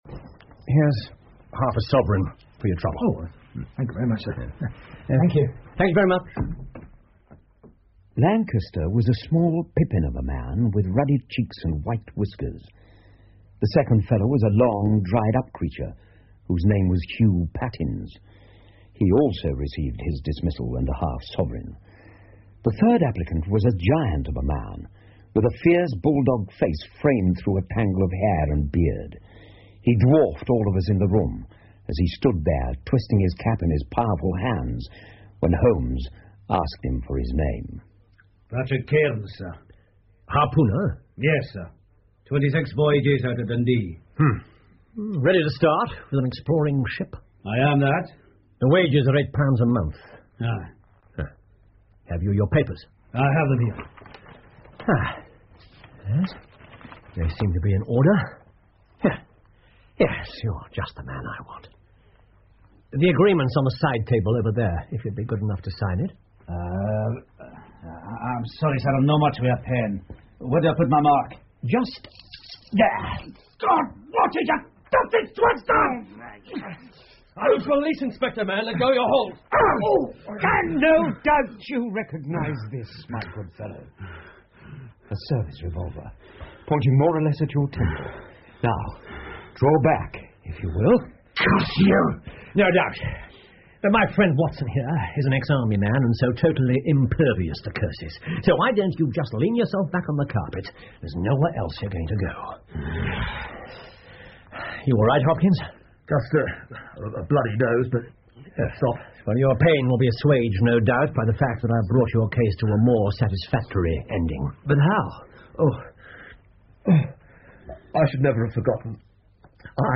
福尔摩斯广播剧 Black Peter 7 听力文件下载—在线英语听力室